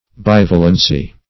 bivalency - definition of bivalency - synonyms, pronunciation, spelling from Free Dictionary Search Result for " bivalency" : The Collaborative International Dictionary of English v.0.48: Bivalency \Biv"a*len*cy\, n. (Chem.) The quality of being bivalent.